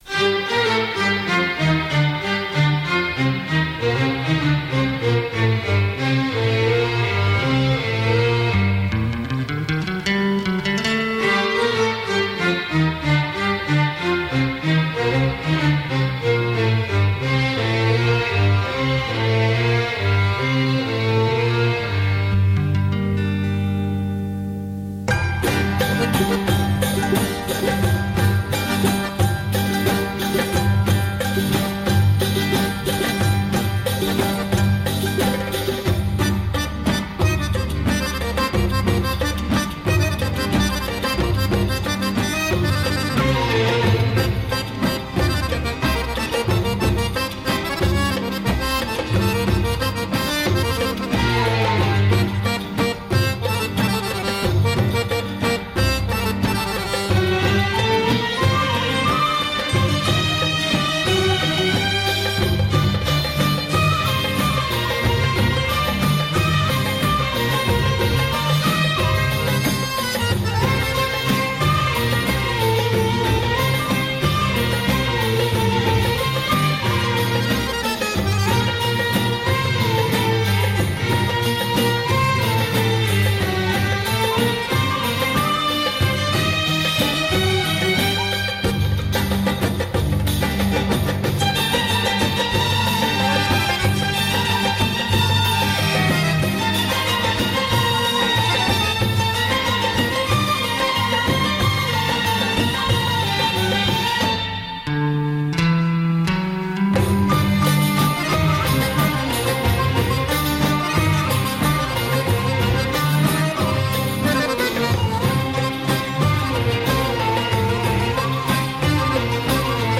Orchestra
organ